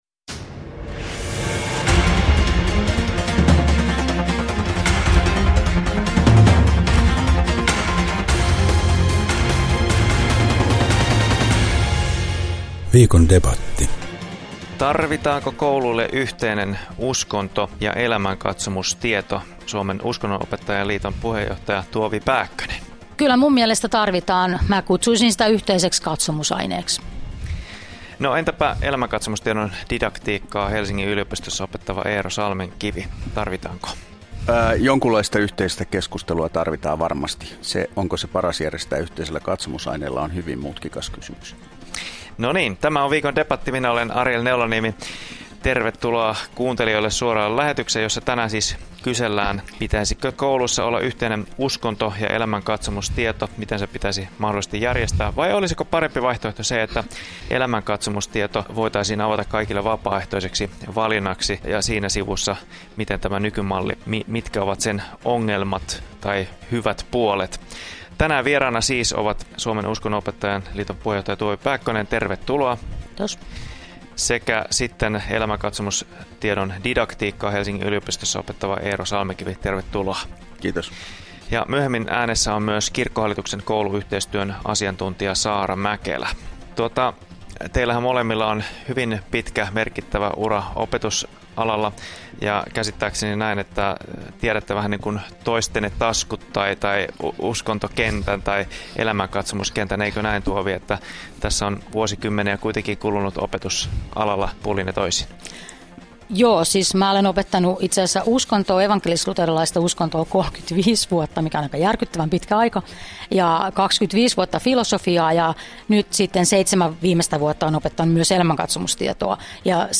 Kuuntele debatti [Radio Dei 21.4.2021]
viikon-debatti-nettiin.mp3